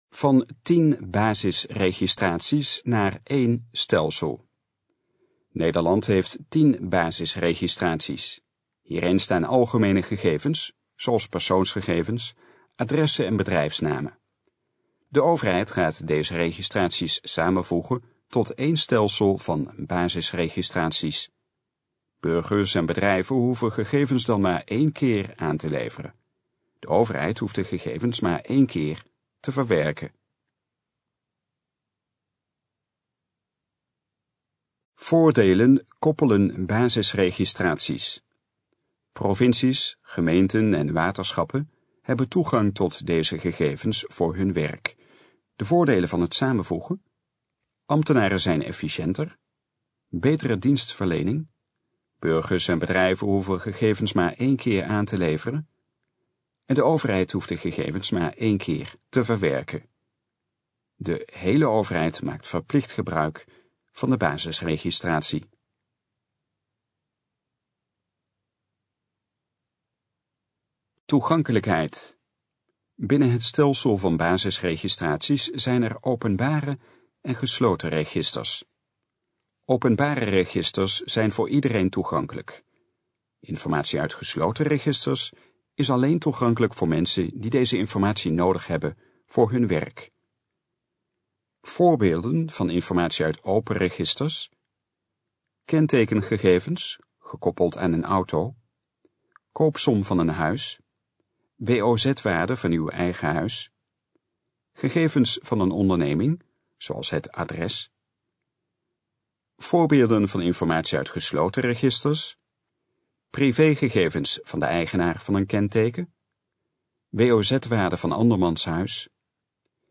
Gesproken versie van Van 10 basisregistraties naar 1 stelsel
In het volgende geluidsfragment hoort u hoe de overheid de 10 basisregels gaat samenvoegen tot 1 stelsel. Het fragment is de gesproken versie van de informatie op de pagina Van 10 basisregistraties naar 1 stelsel.